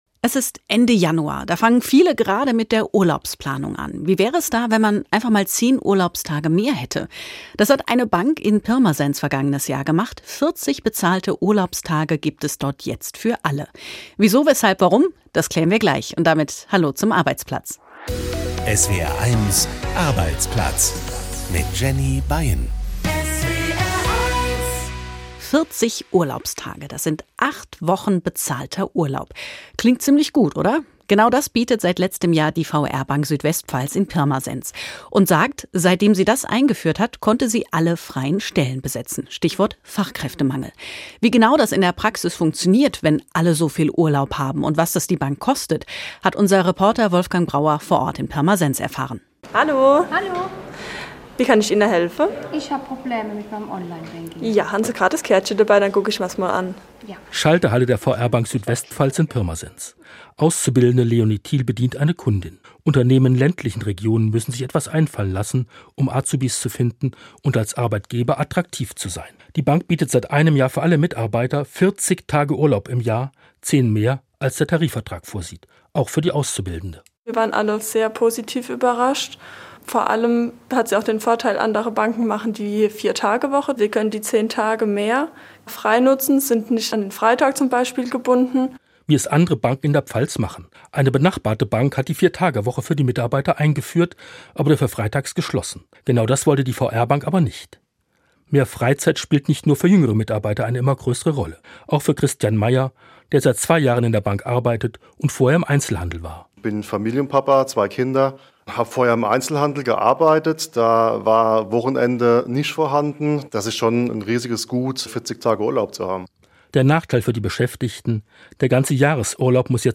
Das Radiomagazin für Arbeitnehmer und Arbeitgeber, für Betroffene und Beobachter, für Eltern und Lehrer, Auszubildende und Ausbilder. Reportagen aus Betrieben, Hintergründe, Meinungen, Urteile und Interviews.